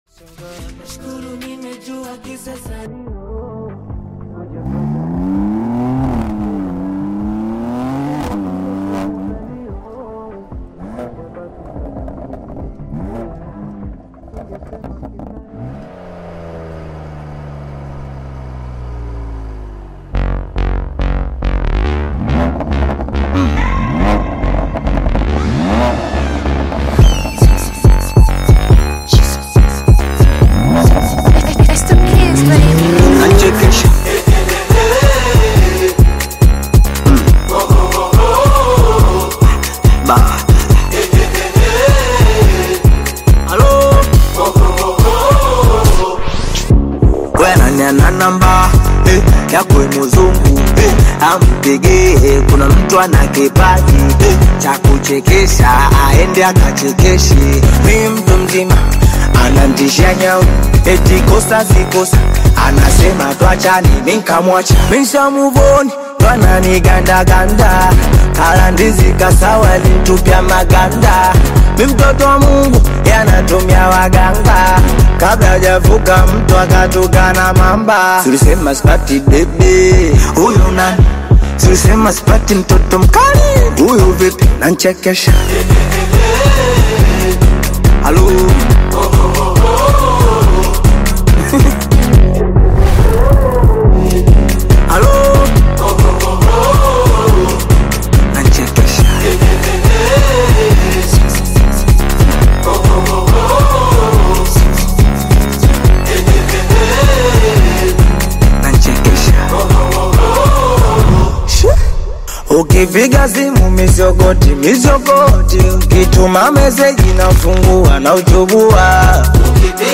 smooth melodies, catchy hooks, and heartfelt lyrics